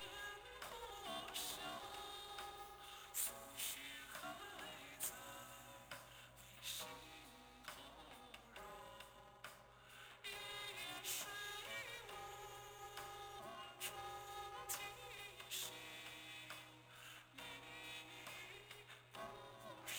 There are three pcmd3140 chips on my device, each connected to two microphones. everyone The audio collected is distorted, and I have no idea what is causing this issue.